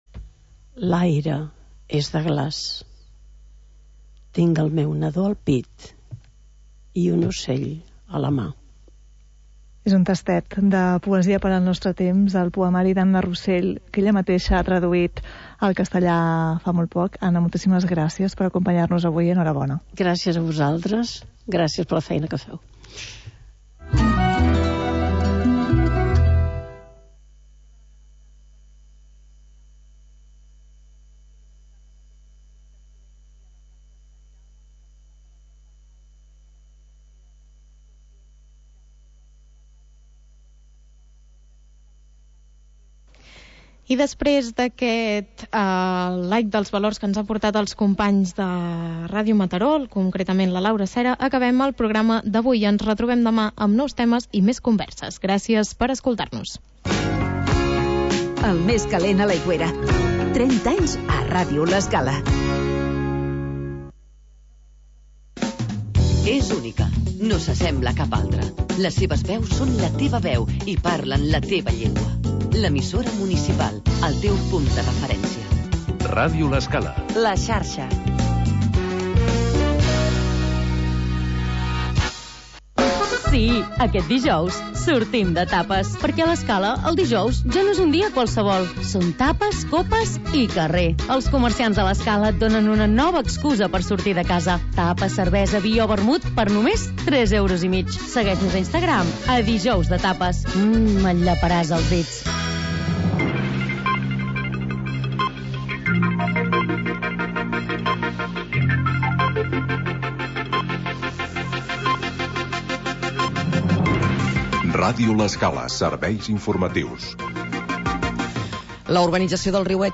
Noticiari d'informació local